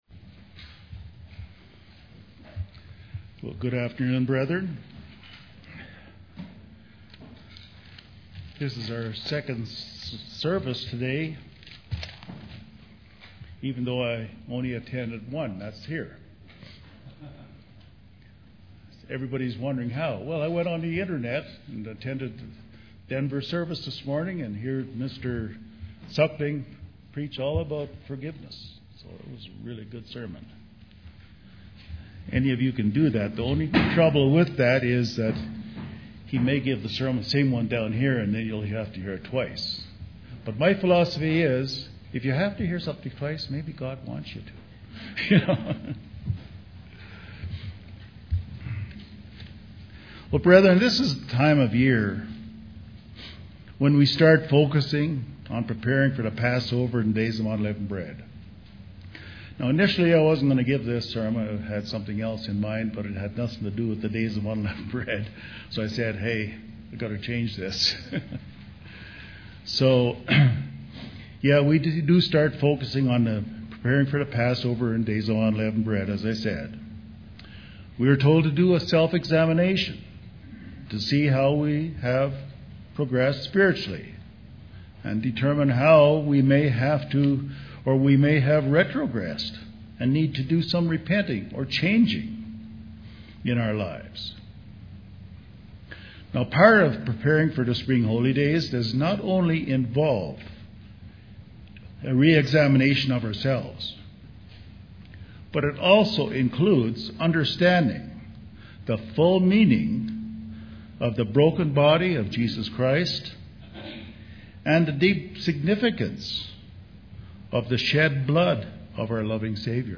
This sermon covers many significant aspects of the broken body and shed blood of our Savior, Jesus Christ.
Given in Colorado Springs, CO